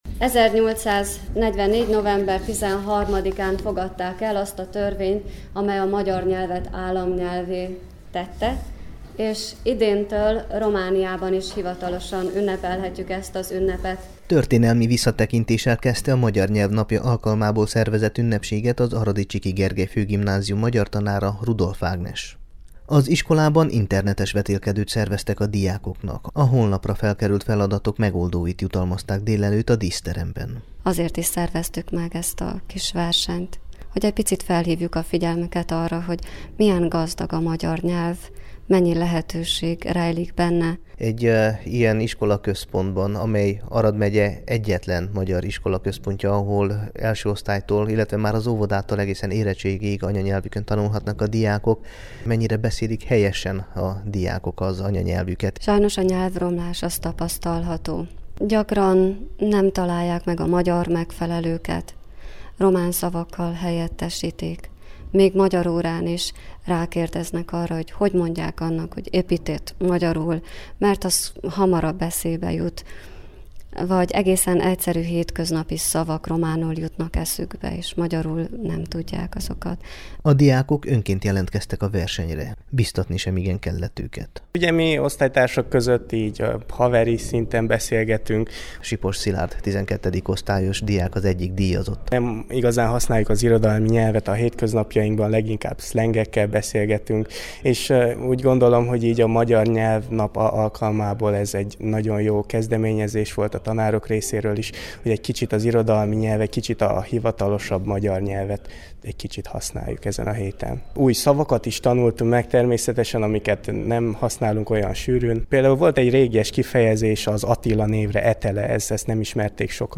Az aradi magyar iskolaközpontban magyar anyanyelvi vetélkedőt rendeztek, aminek a díjkiosztó ünnepségét tartották ma, illetve az elmúlt tanév magyar tantárgyversenyein résztvevőket jutalmazták meg.